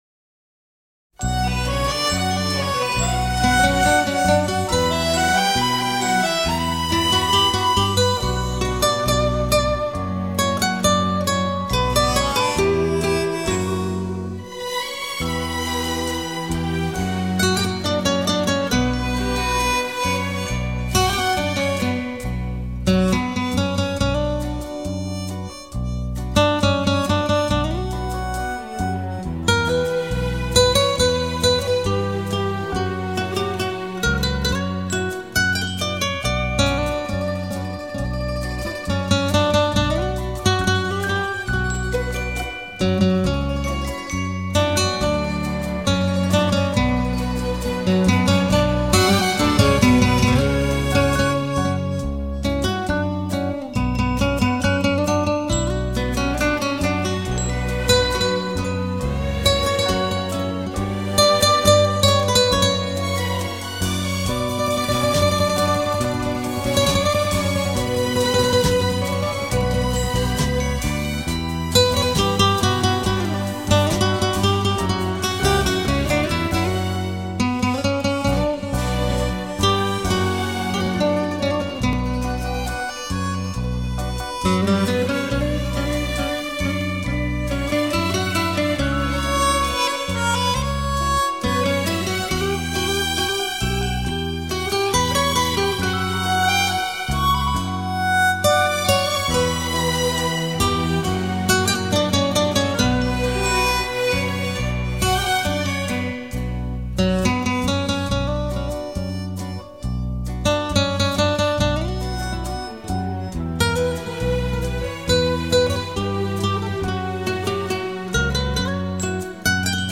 0167-吉他名曲红色生命.mp3